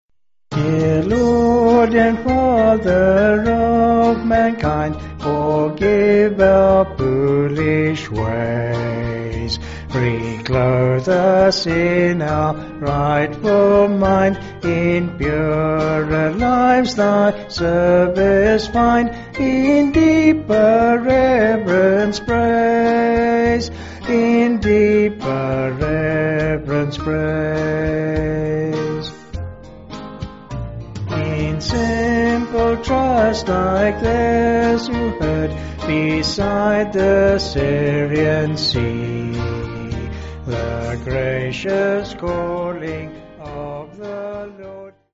5/Eb-E
Vocals and Band